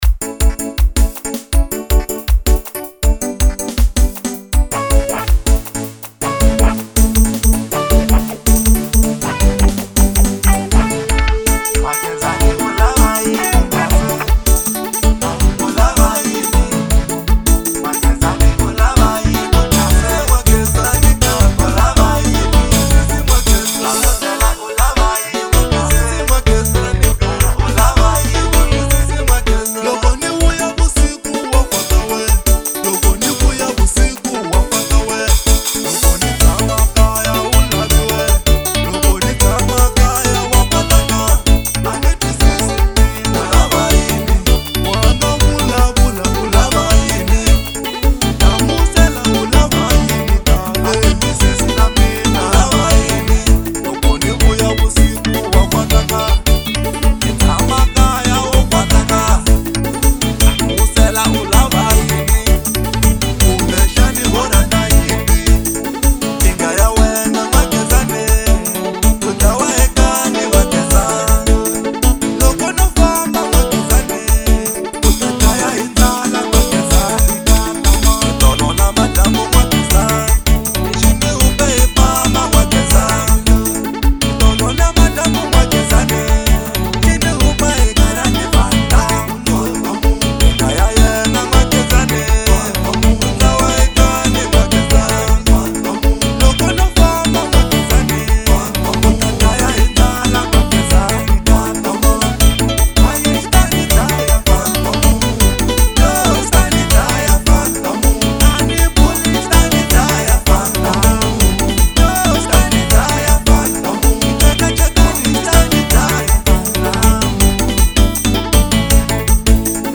03:43 Genre : Xitsonga Size